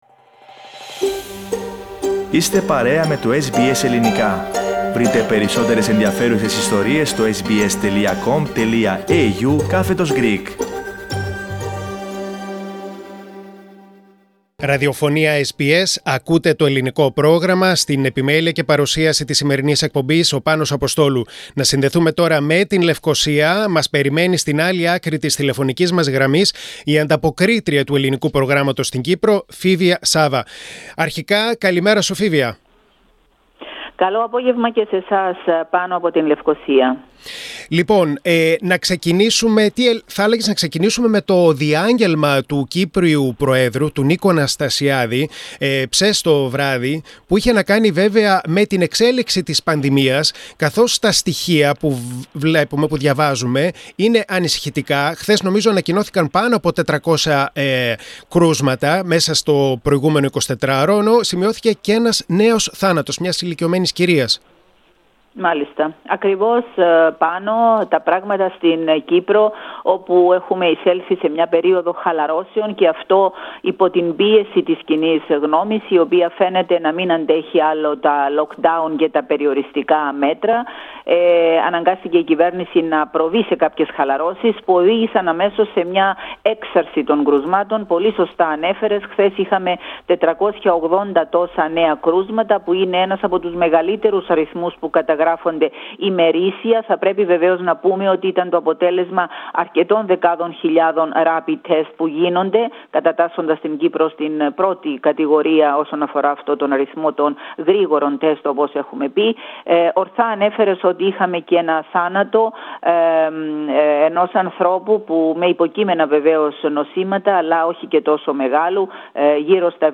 Διάγγελμα Αναστασιάδη για τα γεγονότα στη Λεμεσό
Διάγγελμα απηύθυνε ο πρόεδρος της Κυπριακής Δημοκρατίας, Νίκος Αναστασιάδης, με αφορμή το καρναβαλικό πάρτι της Λεμεσού.